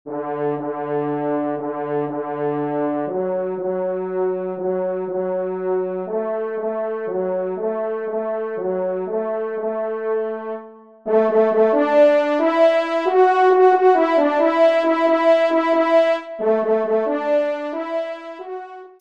Pupitre 1°Trompe